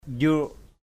/ʄru:ʔ/ ( đg.) nhúng = tremper, plonger dans.